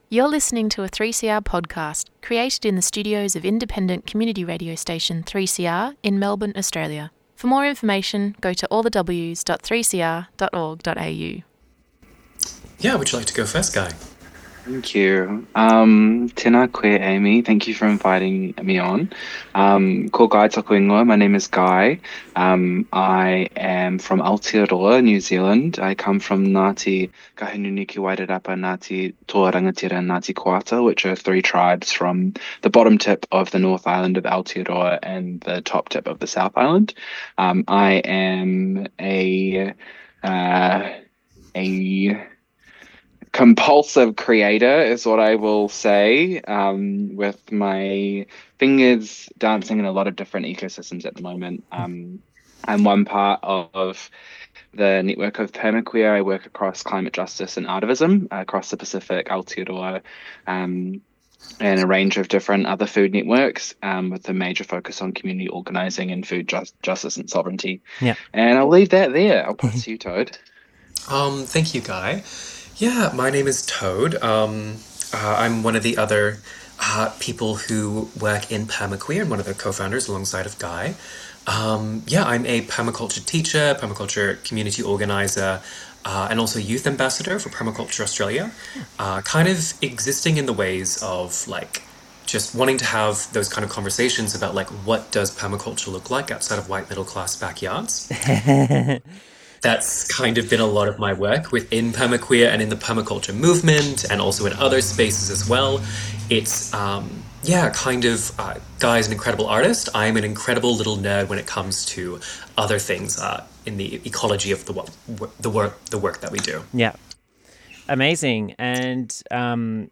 Kicking Fences with PermaQueer (Complete interview with extra bits!)